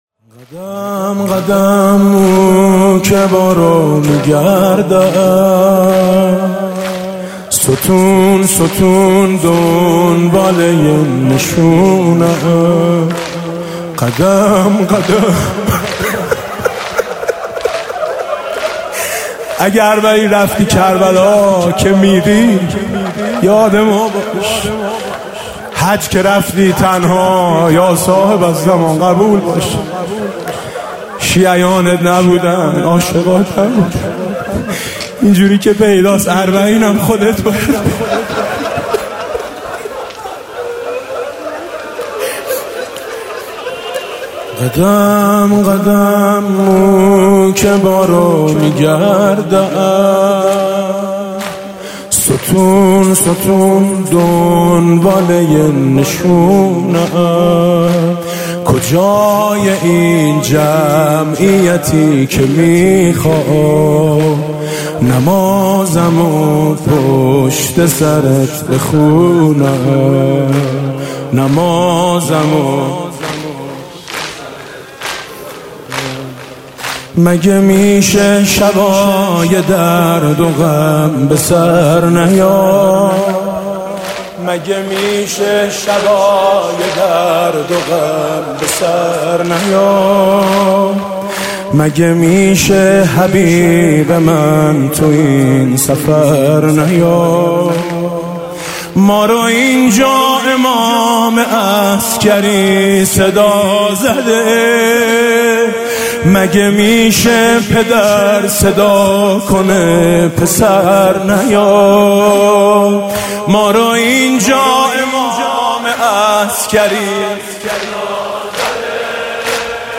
محرم 99 - شب دوم - زمینه - ای آقا عمود چندمی؟